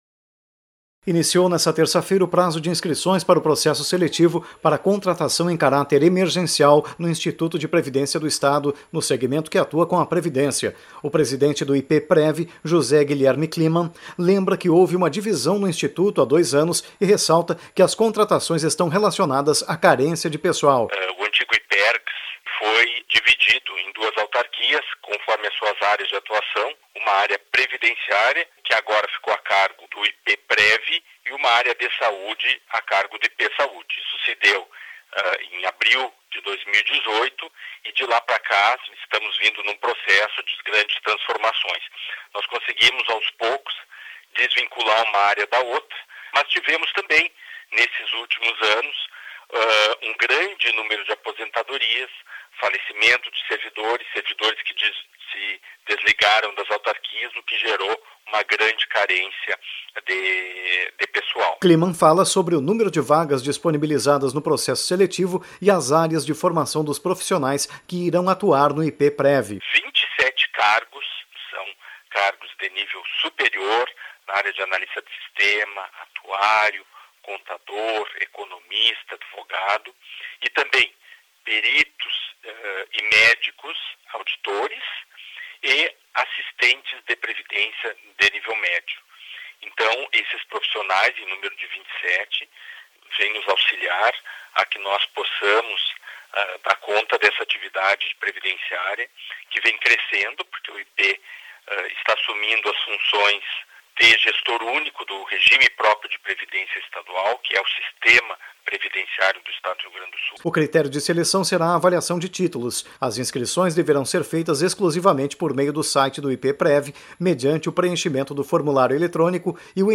Entrevista do presidente do IPE Prev à rádio Web